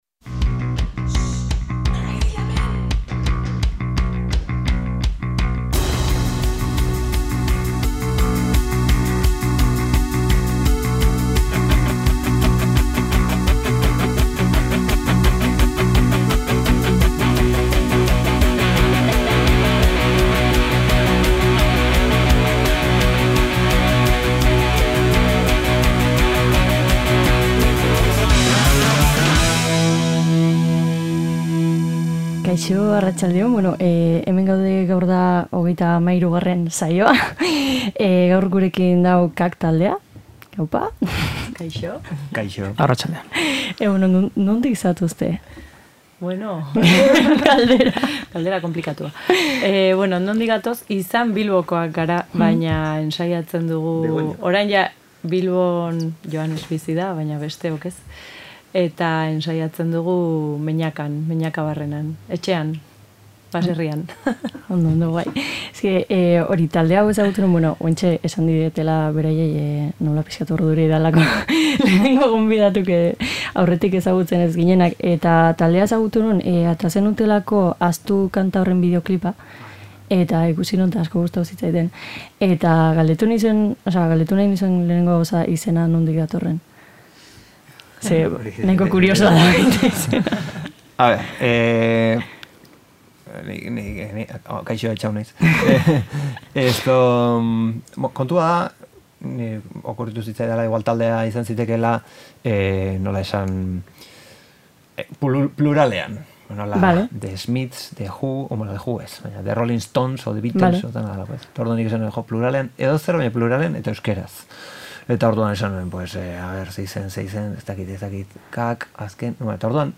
Izena bezala, pentsamenduak pluralak izan dira eta solasaldi ederra sortu da irratian.
Hainbat gai izan dira hizpide elkarrizketa honetan eta bide batez, kanta zerrenda polita sortu dugu. Gainera, disko berria aurkeztu digute, jada kalean dagoena.